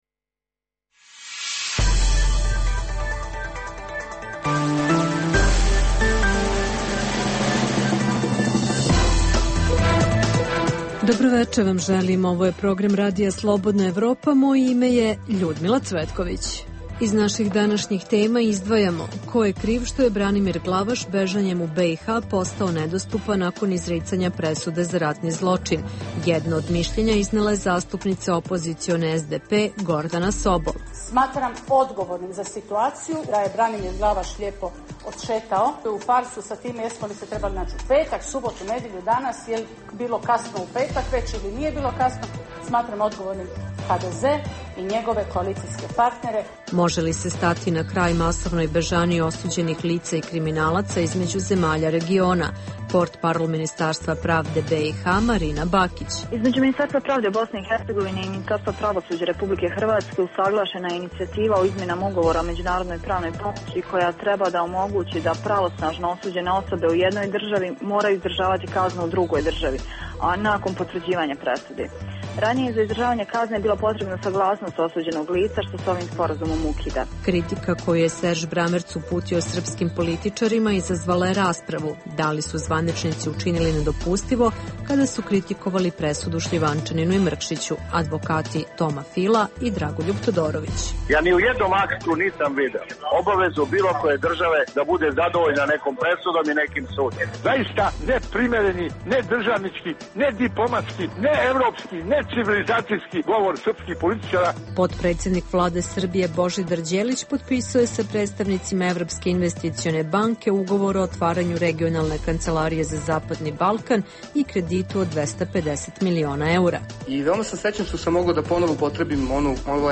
Emisija o dešavanjima u regionu (BiH, Srbija, Kosovo, Crna Gora, Hrvatska) i svijetu. Istražujemo da li će Hrvatska uspeti da Branimira Glavaša sprovode na izdržavanje kazne za ratni zločin, koje su posledice skrivanja Glavaša u BiH, kao i šta se može očekivati od posete Beogradu haškog tužioca Serža Bramerca, a čućete i izvode iz susreta Visokog predstavnika u BiH sa studentima na temu evropskih integracija.